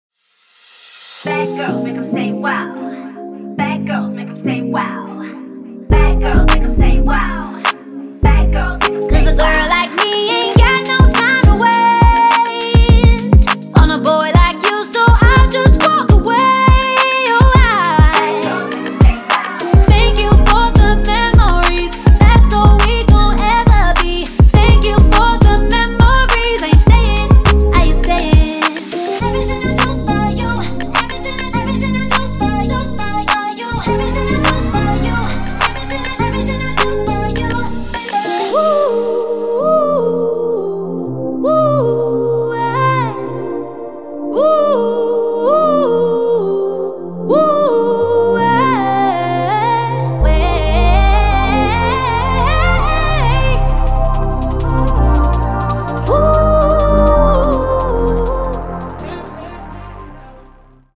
recorded in-house
using the very best high-end microphones and analog hardware